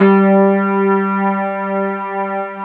FEND1H  G2-R.wav